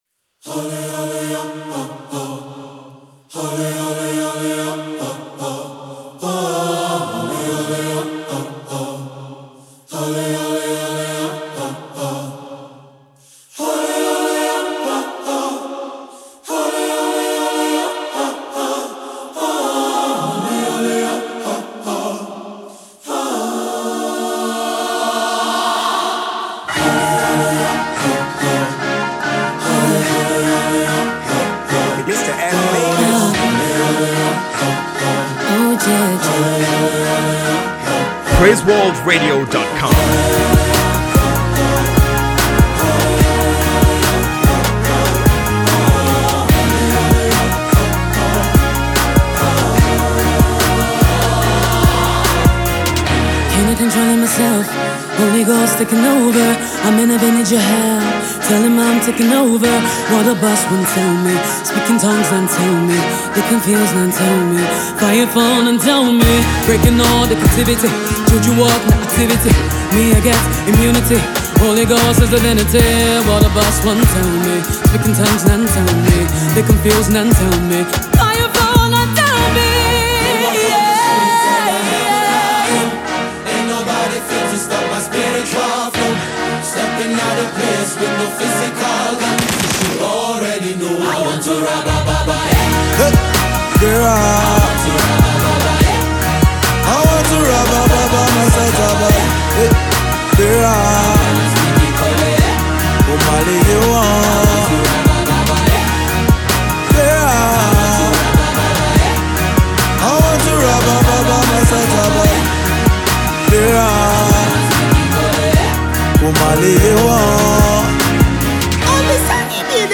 a music group formed in 2016
a 16 member group